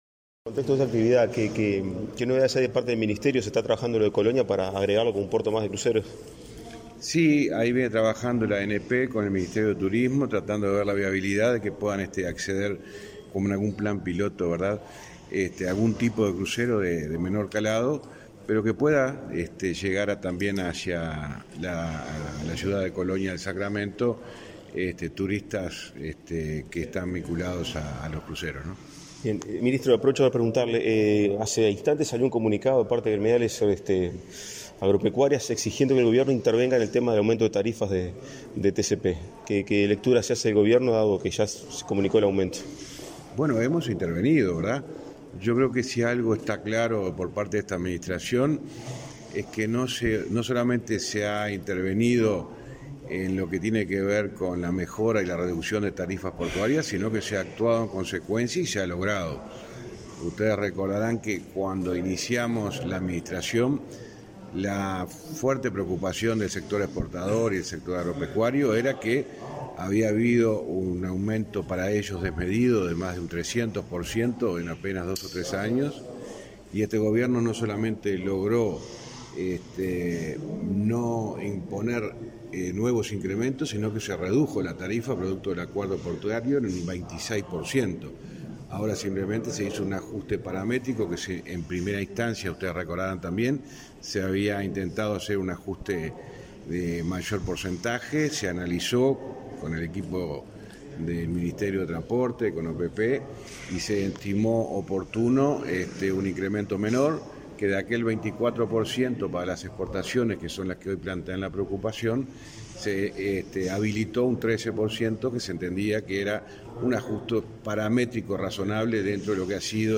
Declaraciones a la prensa del ministro de Transporte y Obras Públicas, José Luis Falero
Tras el evento, el jerarca realizó declaraciones a la prensa.